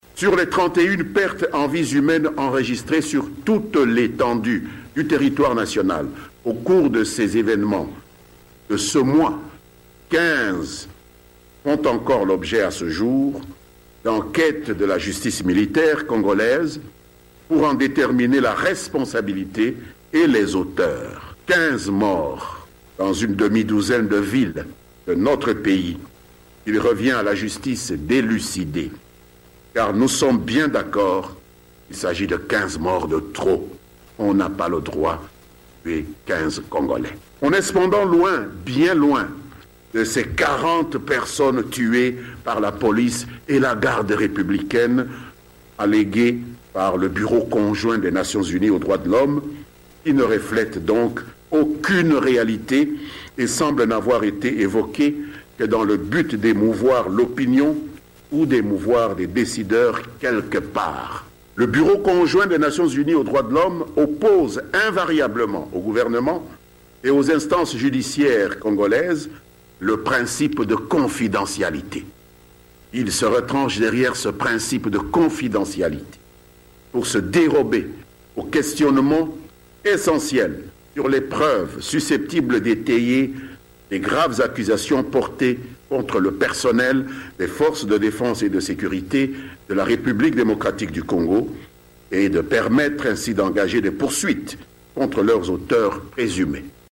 Déclaration de Lambert Mende recueillie par Top Congo